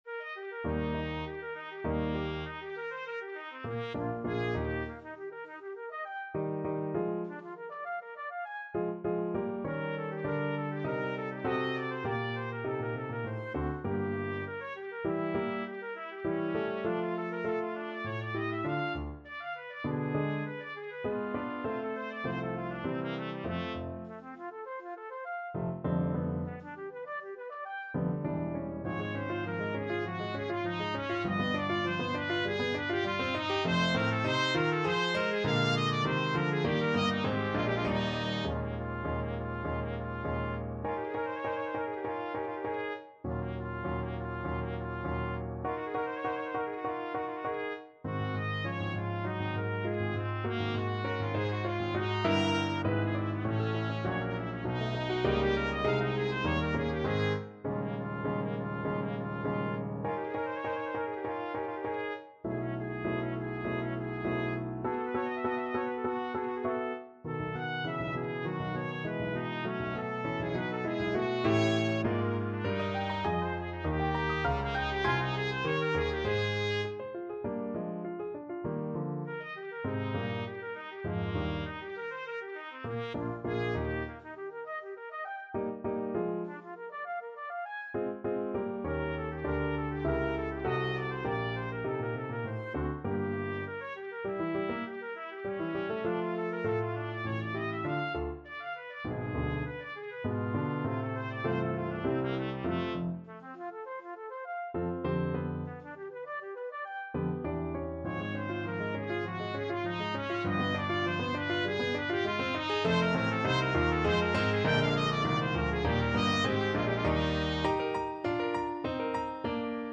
Classical Saint-Saëns, Camille Clarinet Sonata, Op. 167, 2nd Movement, Allegro Animato Trumpet version
Trumpet
~ = 200 Allegro Animato (View more music marked Allegro)
2/2 (View more 2/2 Music)
G4-Ab6
Eb major (Sounding Pitch) F major (Trumpet in Bb) (View more Eb major Music for Trumpet )
Classical (View more Classical Trumpet Music)